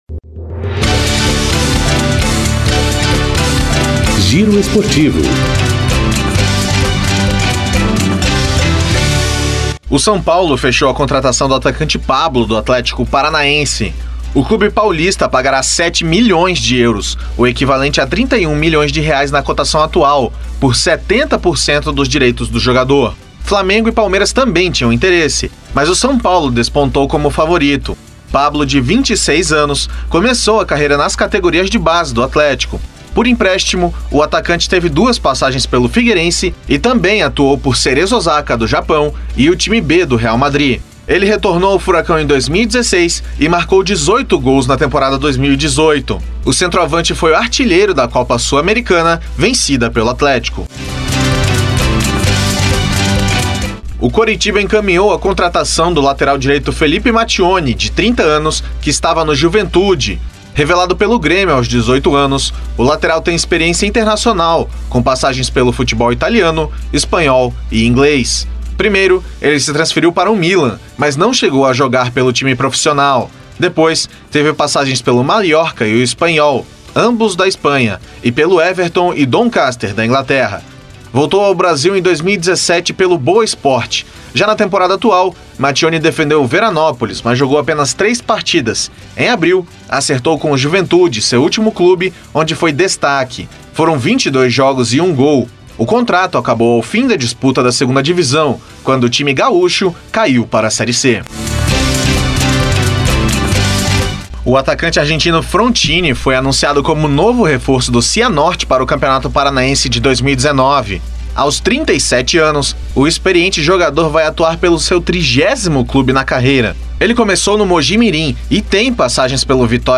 GIRO ESPORTIVO – 19.12 – COM TRILHA